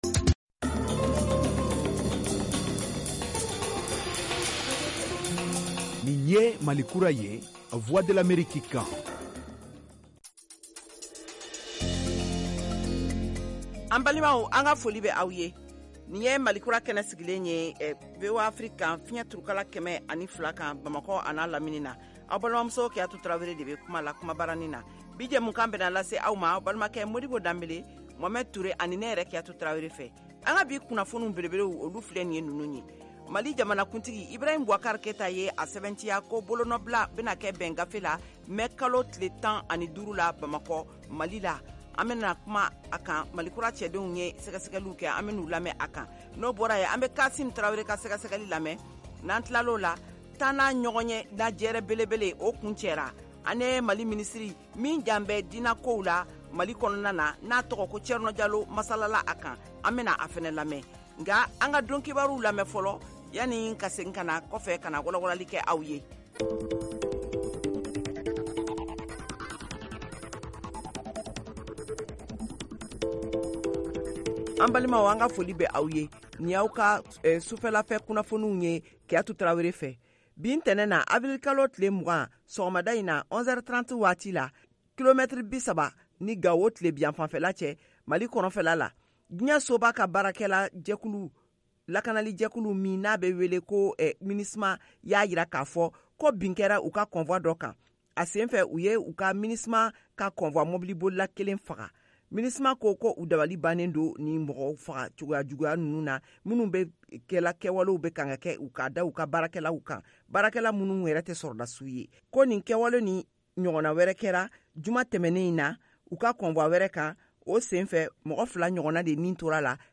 Emission quotidienne en langue bambara
en direct de Washington, DC, aux USA